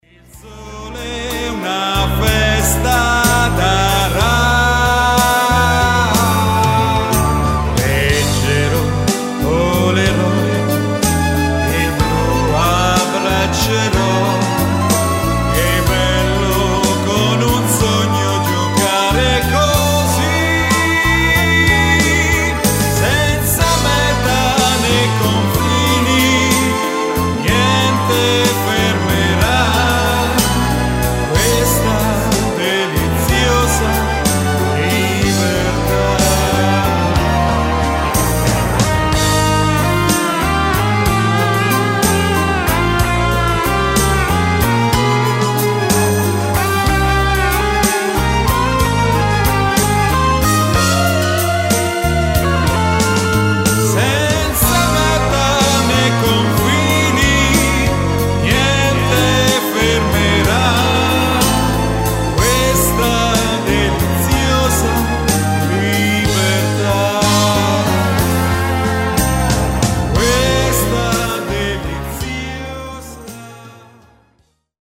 Lento terzinato
Uomo